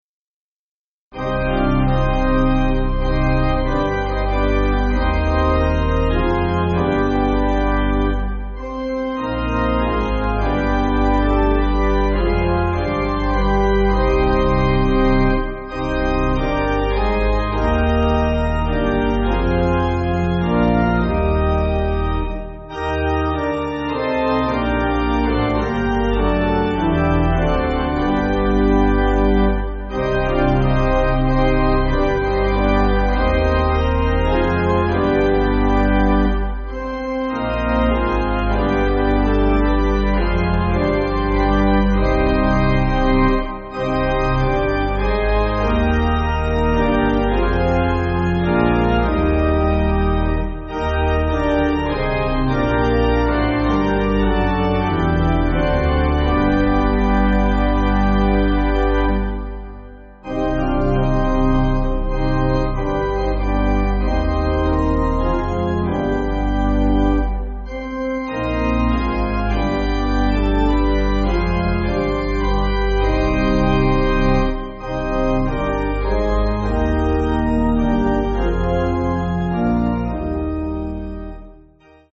(CM)   3/Gm